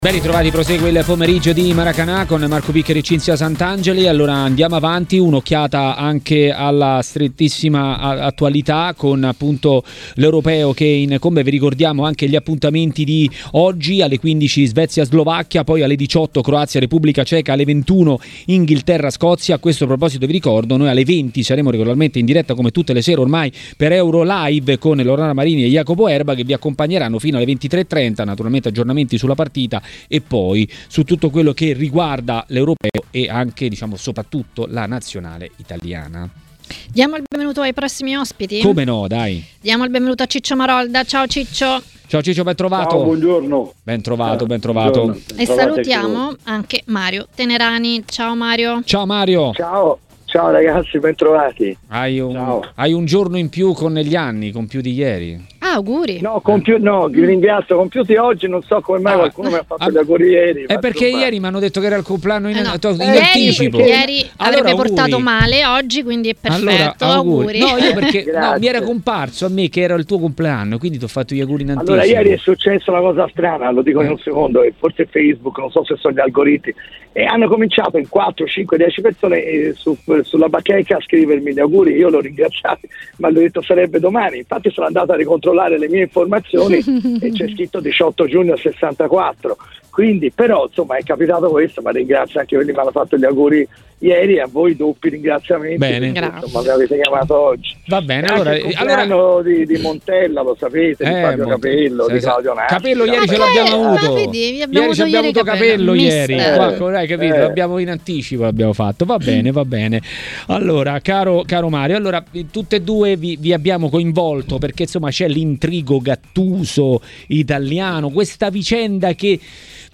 TMW Radio Regia Ascolta l'audio Ospiti